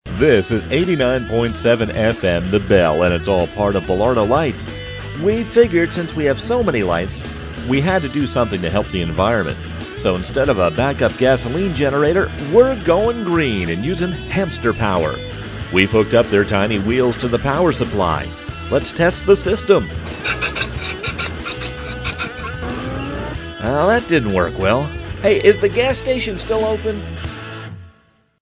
Voice Overs (or Announcements) are an important part of the Belardo Lights Display.
The voice overs heard on Belardo Lights are played through the system speakers, as well as broadcast on the FM airwaves locally on FM 106.1 for visitors driving by the display.
Link - Hamster Power Announcement - 2007